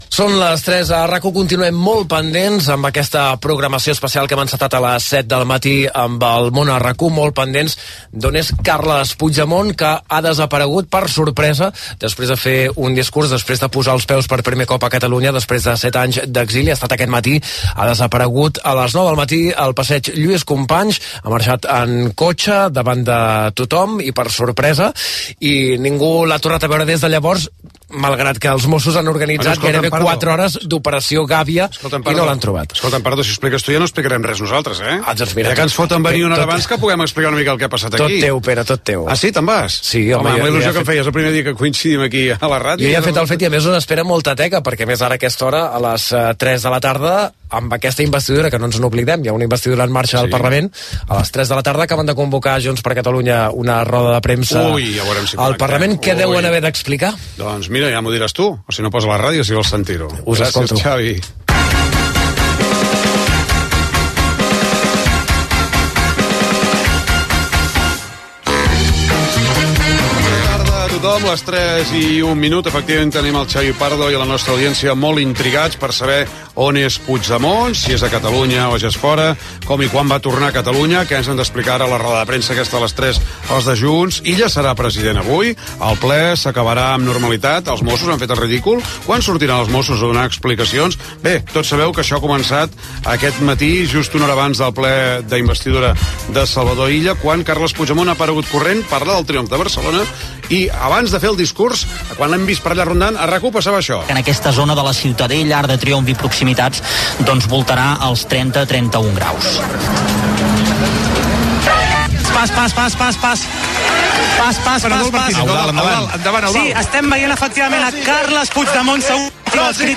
Informació de la desaparició de Carles Puigdemont després de fer un discurs a l'Arc de Triomf de Barcelona, al matí, i de la sessió d'investidura de Salvador Illa com a president de la Generalitat que s'ha de celebrar aquella tarda Gènere radiofònic Entreteniment